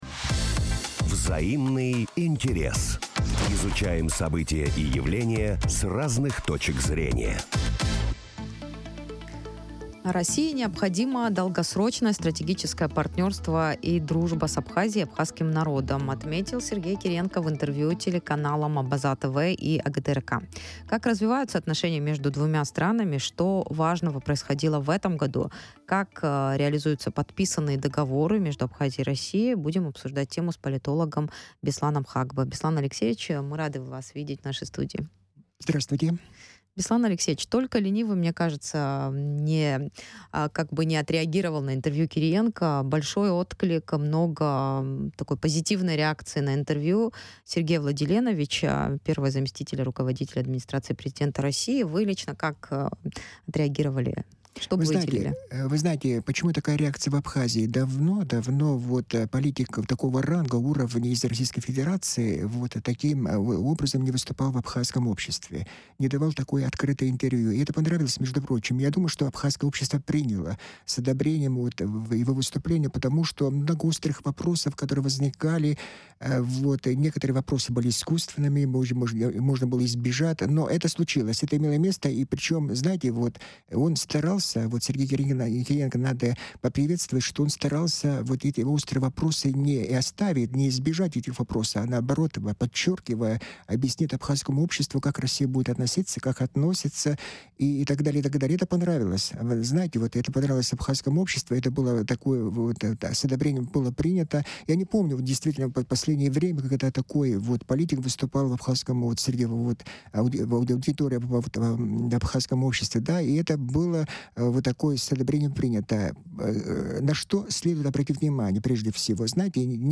России необходимо долгосрочное стратегическое партнерство и дружба с Абхазией и абхазским народом, отметил Сергей Кириенко в интервью "Абхазскому телевидению" и каналу "Абаза-ТВ".